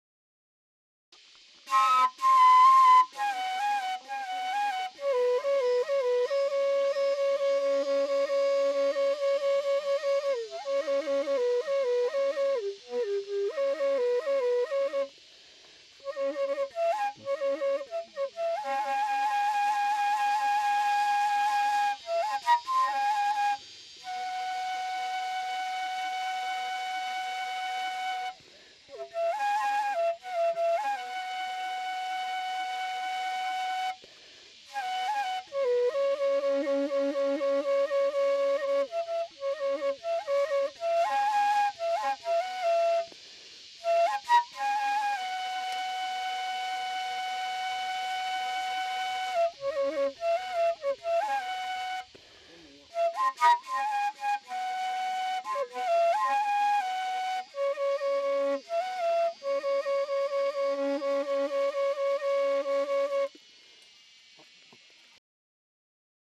flute solo a long two-holed flute with a block whistle, the tune is to send the player's beloved to sleep and wish her happy dreams 1MB
Track 22 Akha flute.mp3